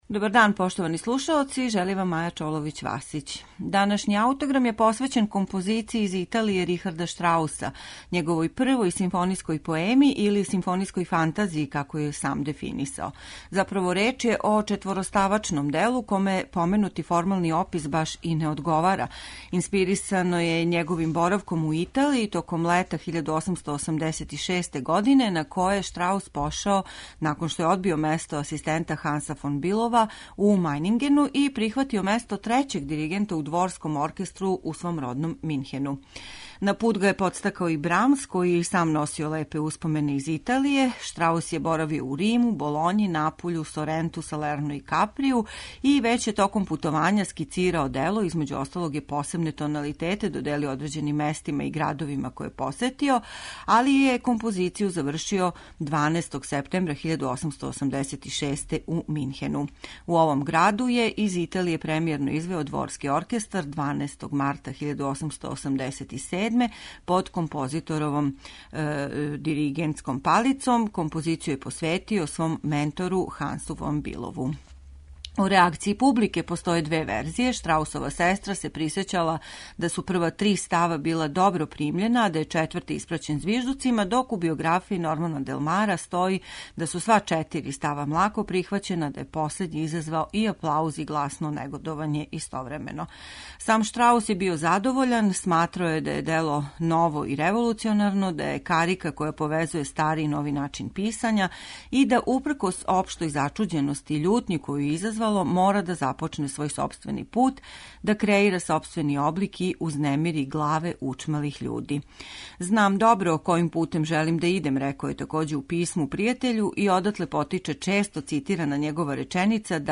Извешће их Словачка филхармонија, а дириговаће Зденек Кошлер.